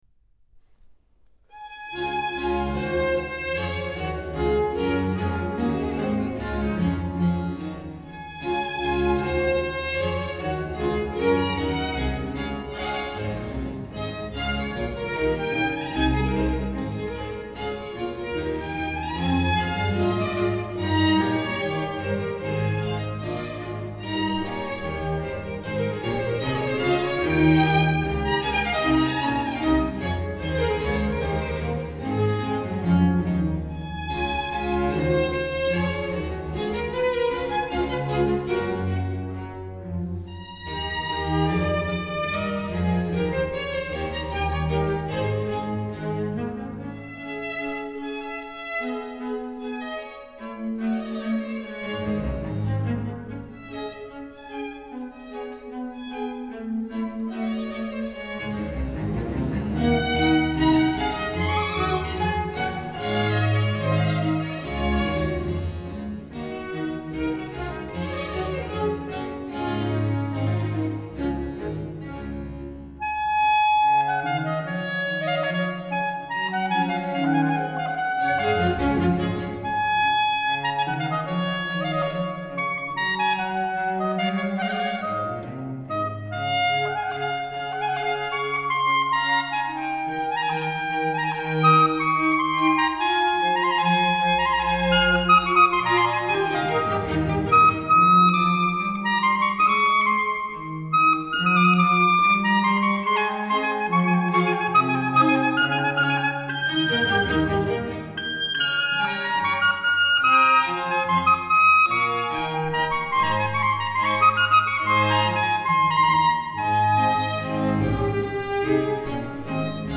Concerto for clarinet and small orchestra
D major